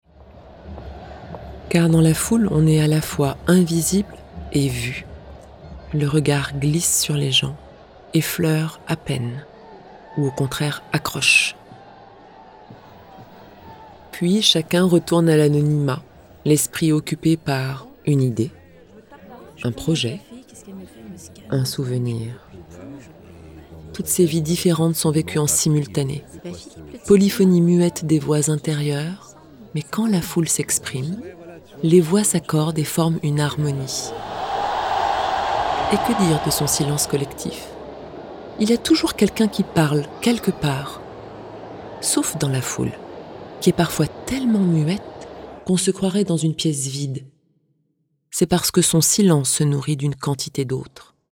Voix off
35 - 40 ans - Mezzo-soprano